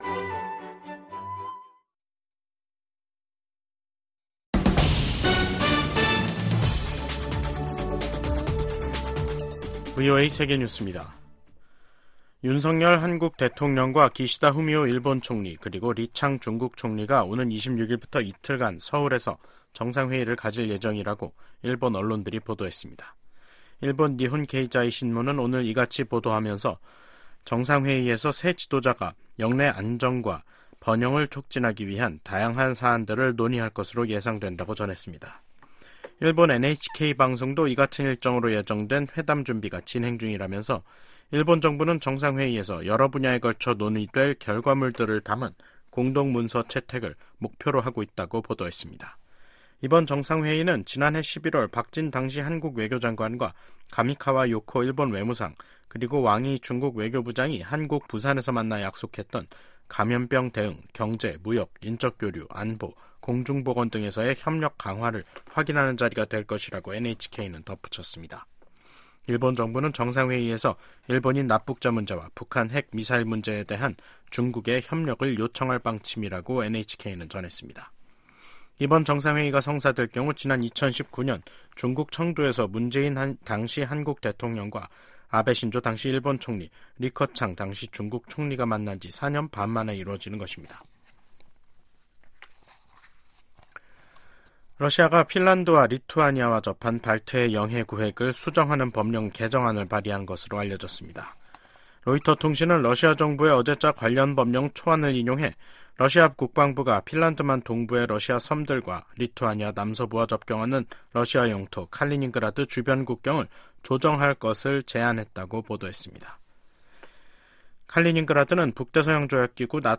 VOA 한국어 간판 뉴스 프로그램 '뉴스 투데이', 2024년 5월 22일 2부 방송입니다. 토니 블링컨 미국 국무장관이 러시아에 대한 북한의 직접 무기 지원을 비판했습니다.